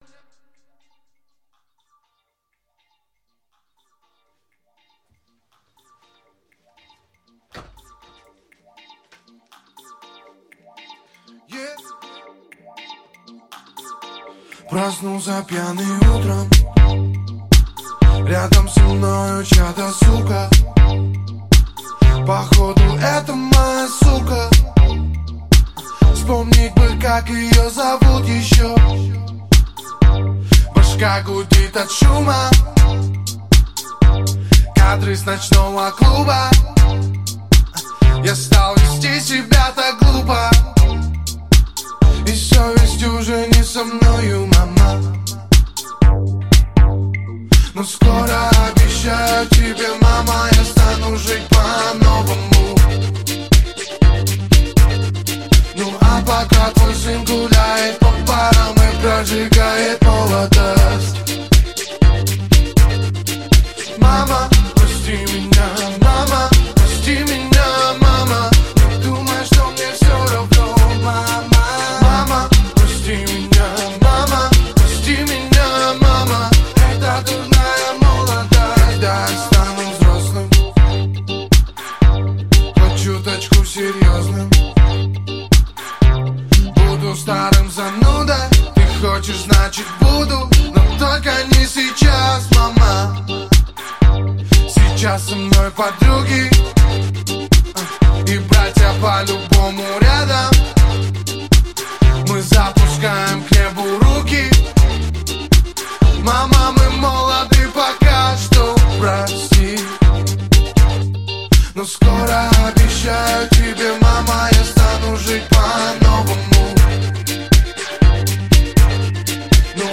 Жанр: Жанры / Русский рэп